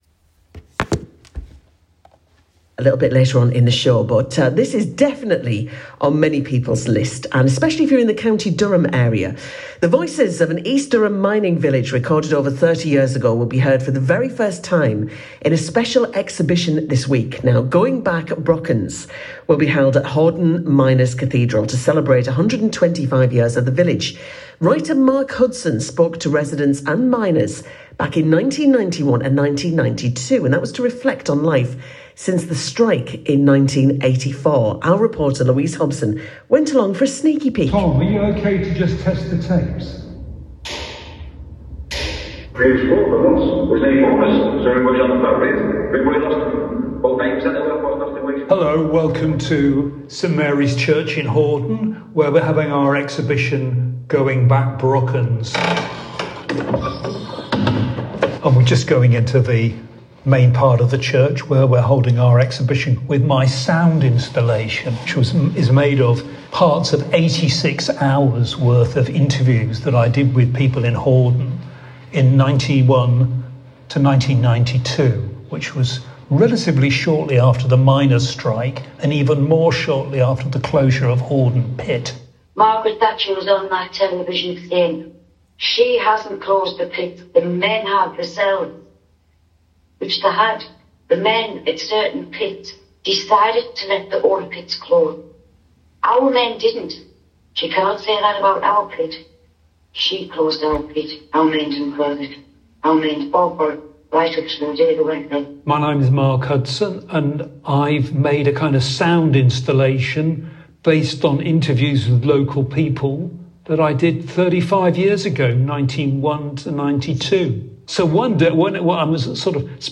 BBC Radio Tees interview: